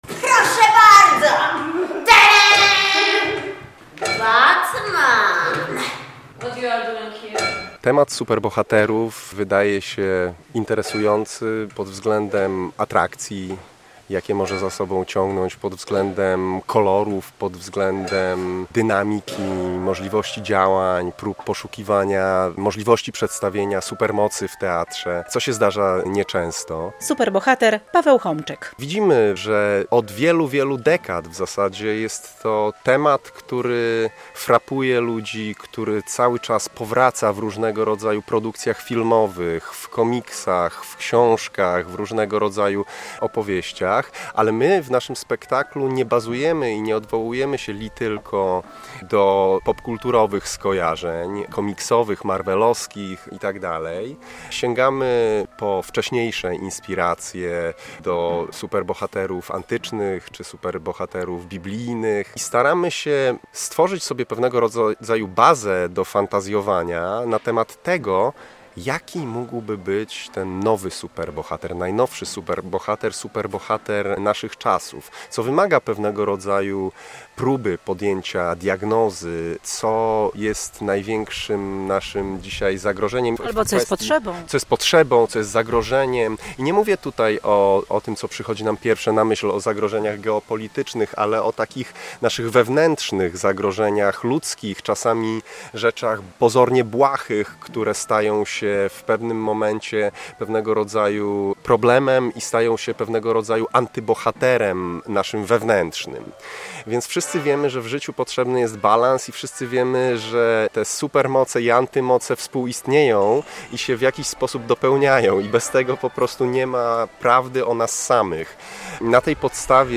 Zbliża się premiera spektaklu "Superheroes" w Siedlisku Kultury 44 w Solnikach - relacja